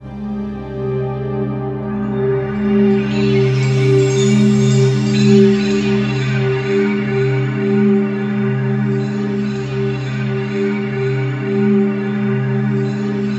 SWEEP05   -R.wav